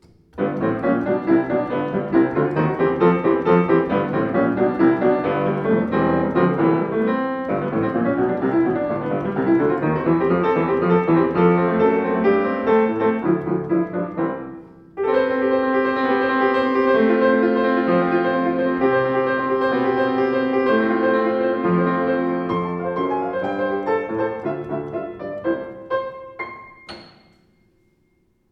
Schöner, ausdruckstarker Klang, angenehme Spielart